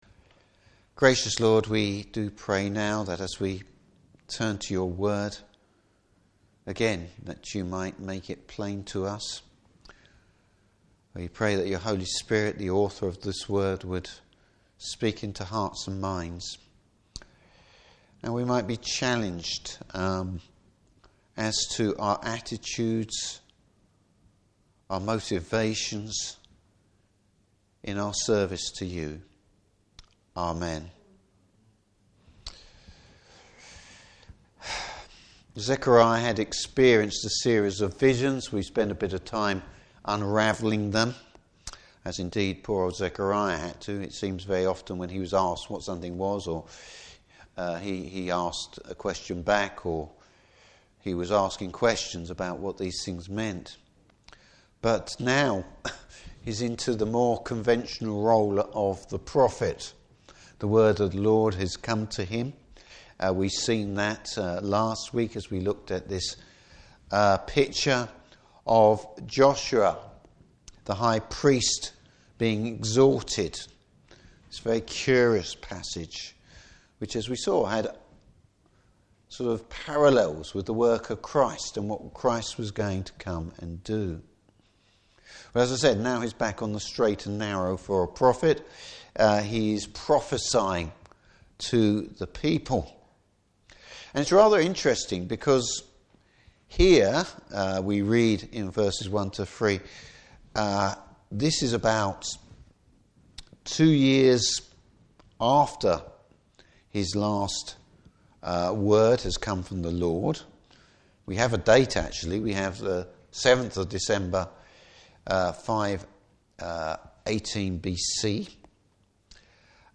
Service Type: Evening Service The Lord questions his people’s motive.